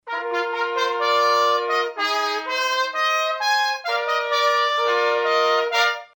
Il brano 'Pappardella' dei musici di Porta Romana.